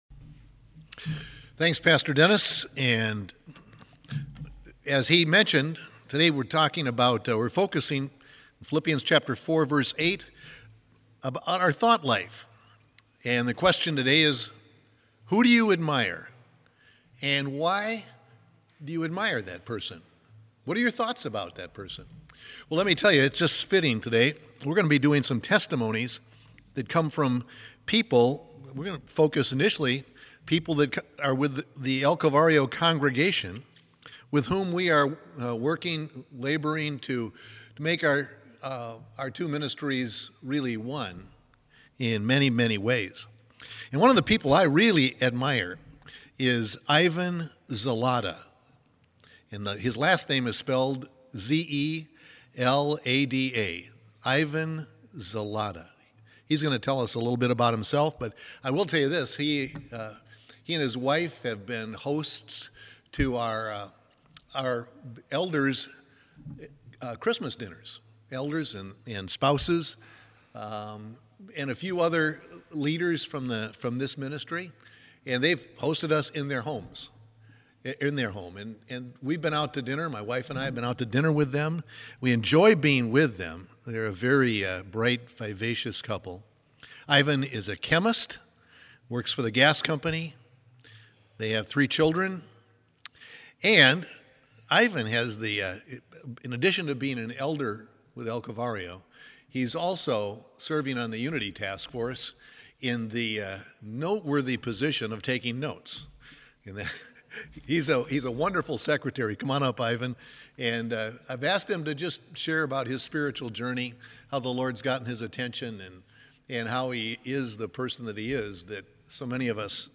A message from the series "The Fear of the Lord."